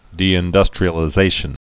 (dēĭn-dŭstrē-ə-lĭ-zāshən)